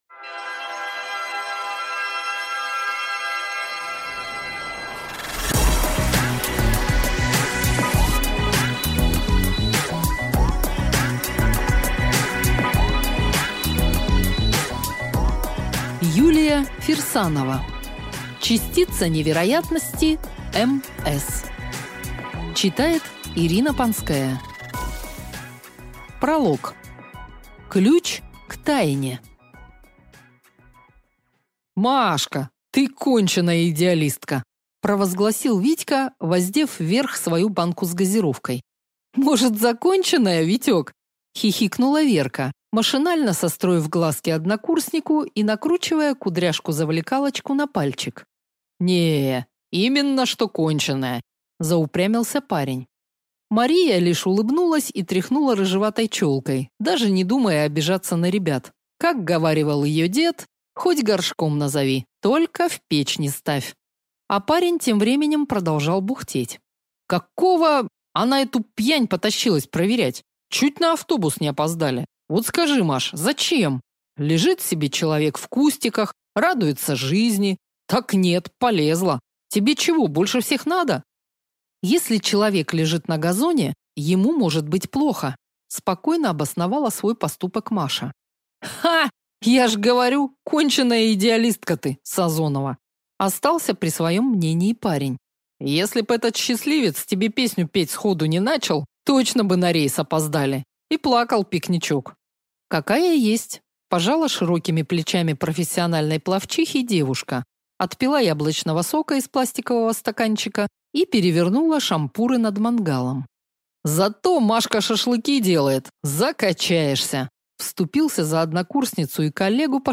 Аудиокнига Частица невероятности МС | Библиотека аудиокниг